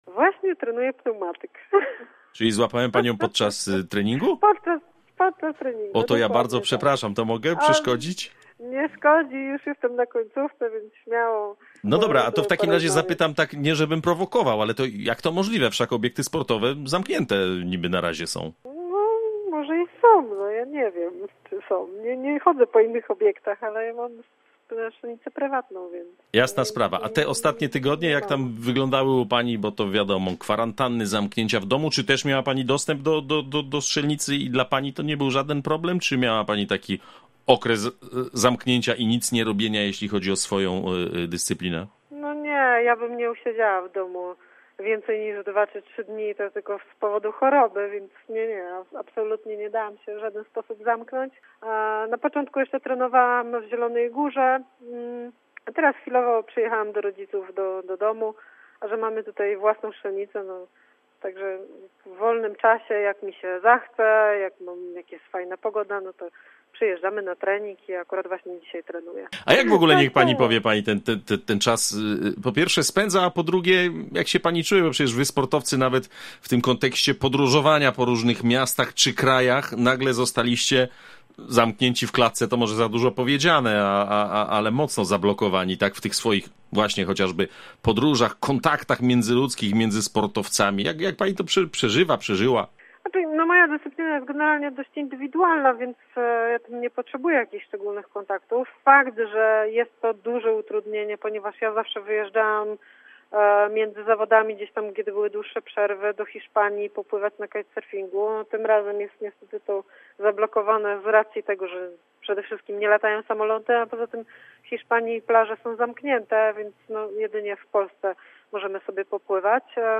Wicemistrzyni olimpijska z Londynu Sylwia Bogacka z Gwardii Zielona Góra mówi w rozmowie z Radiem Zielona Góra, że pandemia koronawirusa i jej skutki nie mają na nią złego wpływu – ani mentalnego, ani fizycznego.